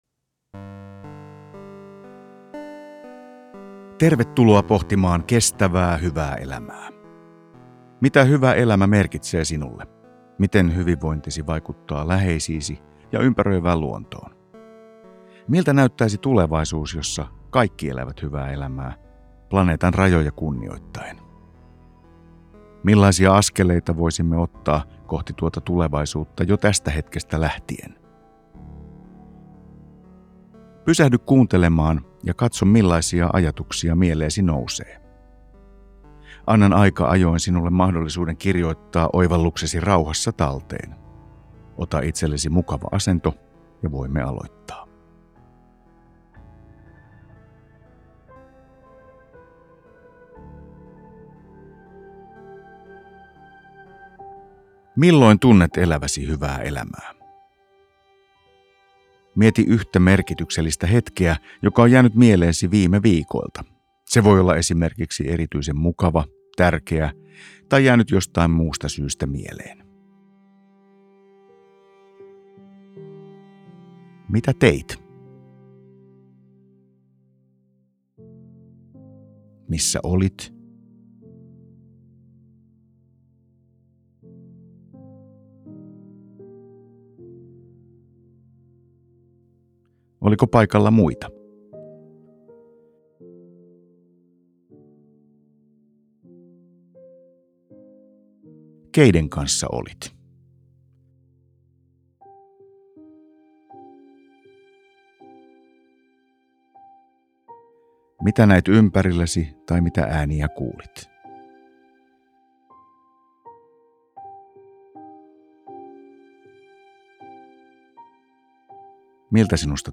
Ratissa tai reissussa? Kuuntele harjoitus: